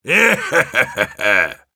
После чего он хвастается своими руками и смеётся.
Зловещий смех 3
Heavy_laughevil03_ru.wav